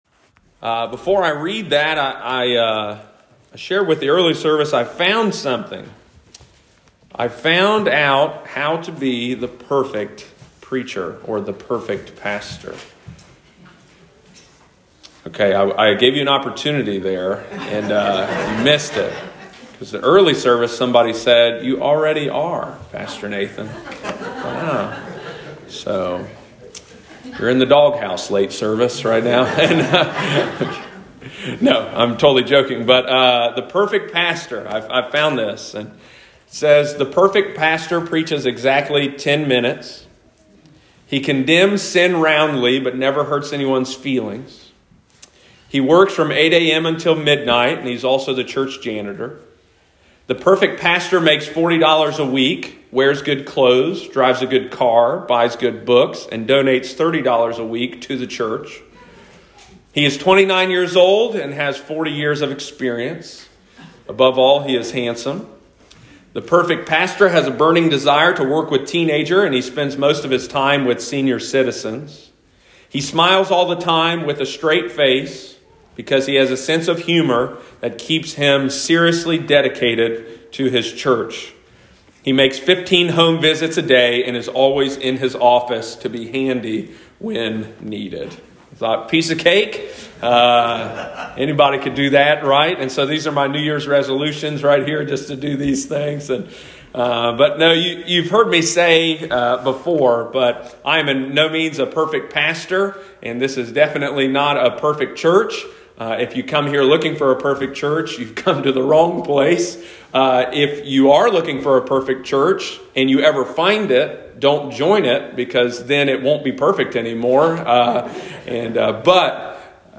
Sunday morning, January 9, 2022.